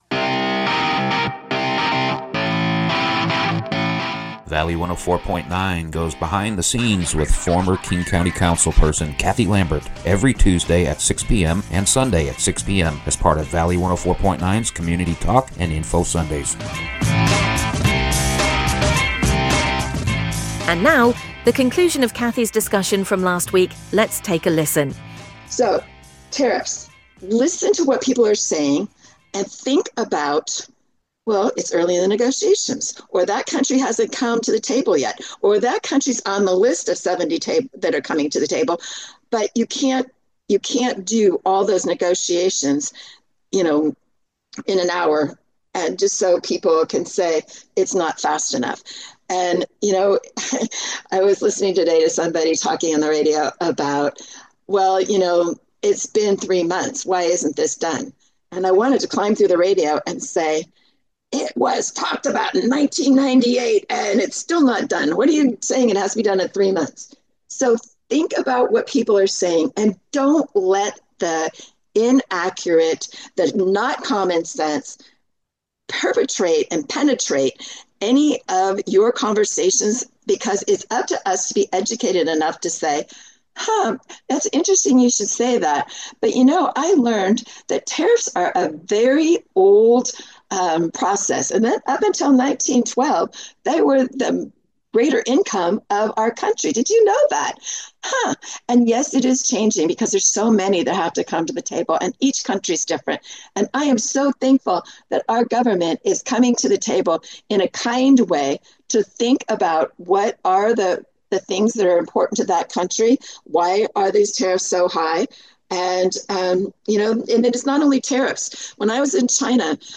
is joined by guests each week and together they examine some aspect of our valley’s past,